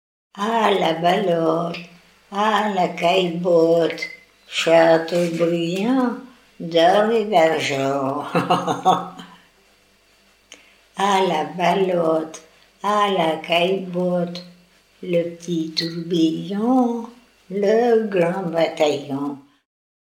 Enfantines - rondes et jeux
Plaine vendéenne
enfantine : jeu de balle